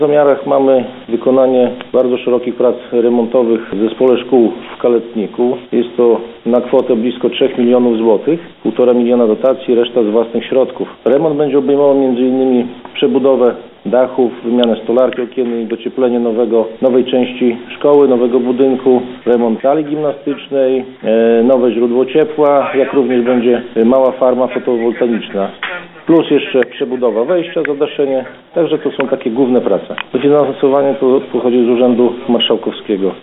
O szczegółach mówił Mariusz Grygieńć, wójt gminy Szypliszki.